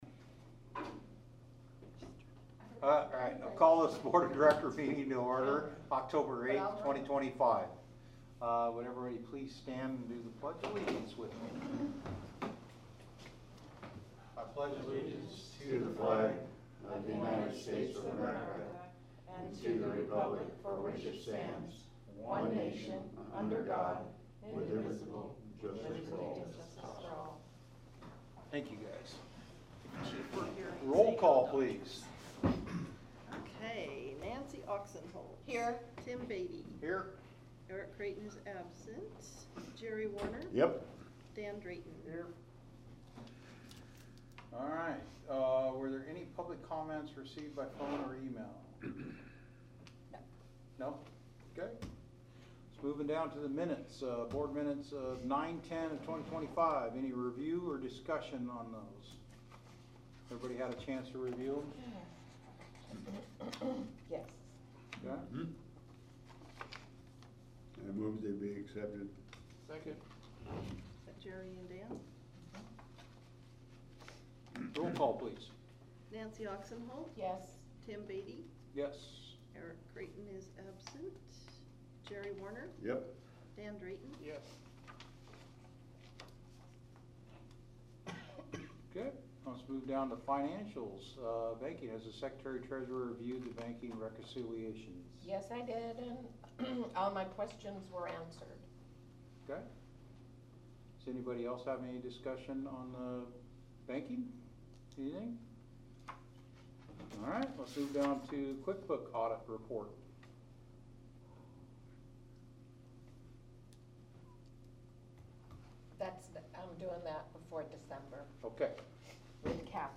Board Meeting
Regular+Board+Meeting+10-8-25.MP3